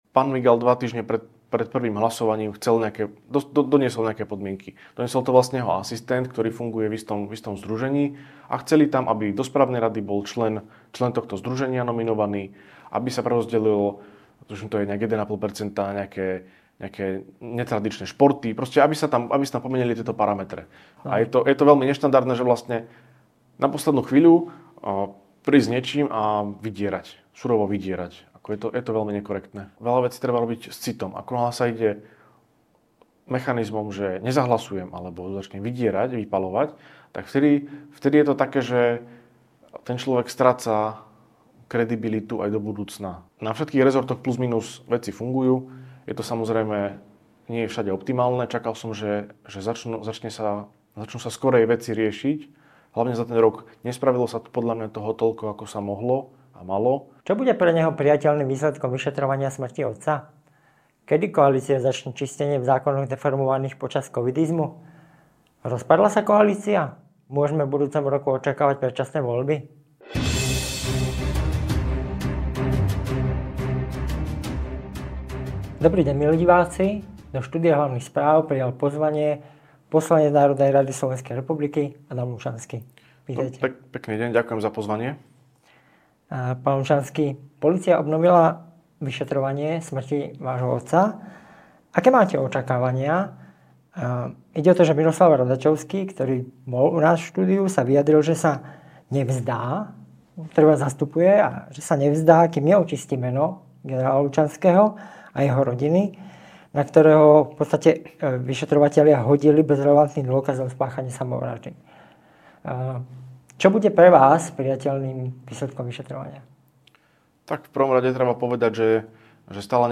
Stiahnite si alebo vypočujte audio záznam rozhovoru .